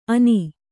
♪ ani